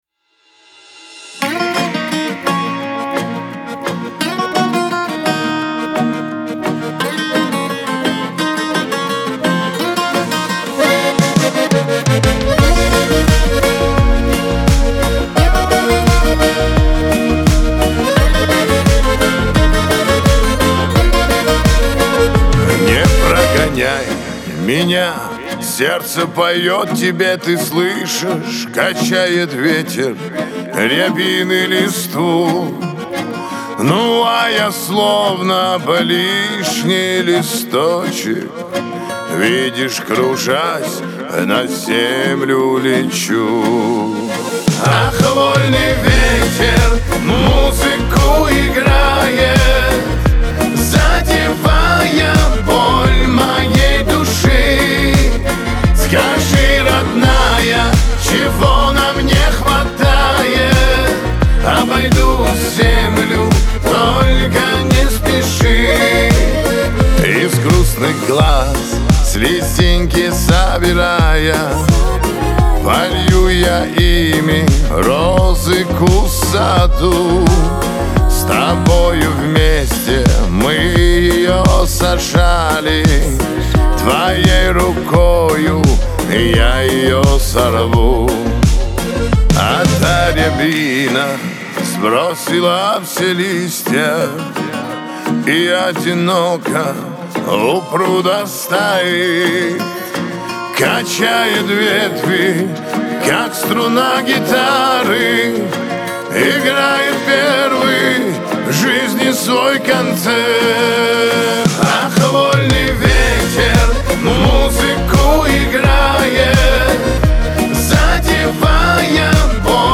Кавказ поп